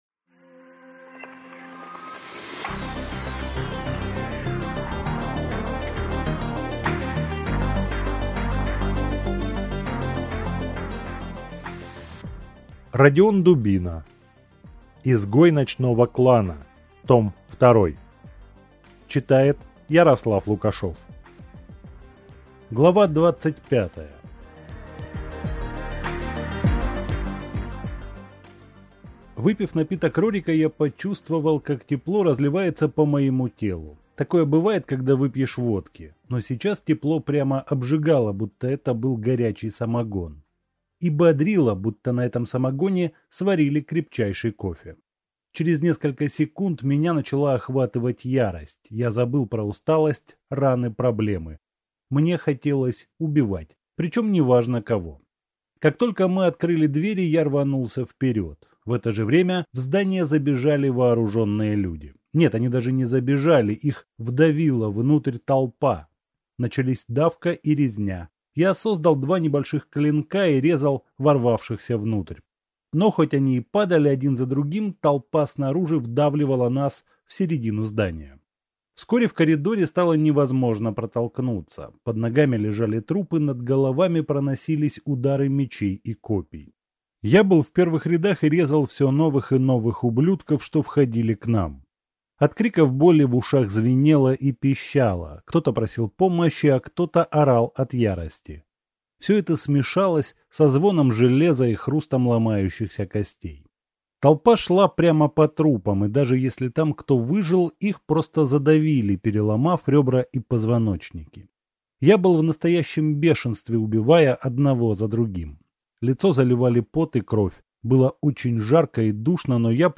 Аудиокнига Изгой ночного клана (том 2) | Библиотека аудиокниг